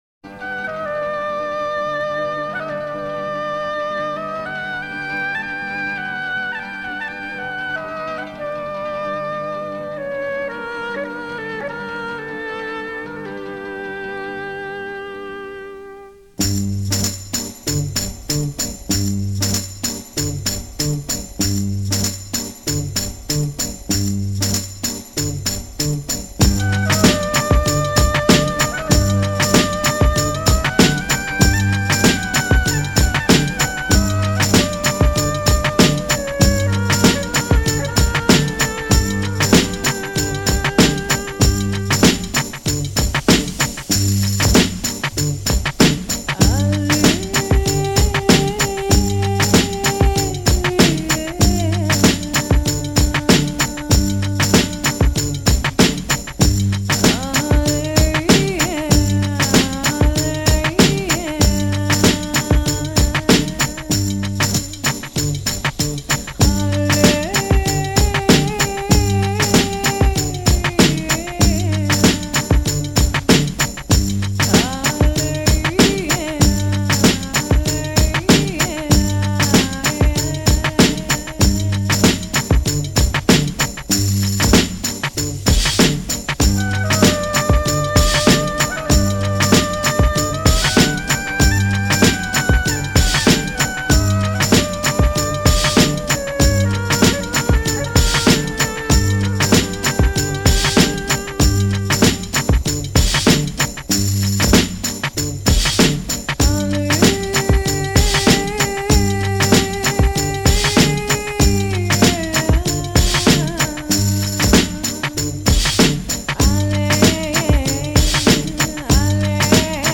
灯火辉煌混合着撼人心动的音乐
最适合做瑜珈，冥想，用餐时，开车时，喝咖啡等商店播放……